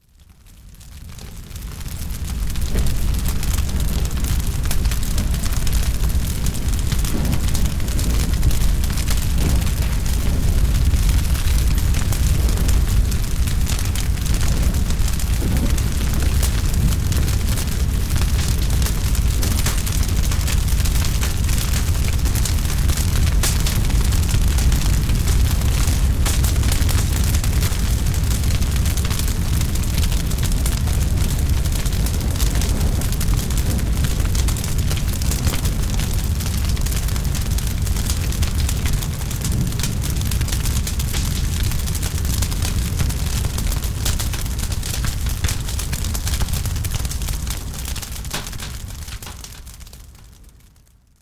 Feu
incendie.mp3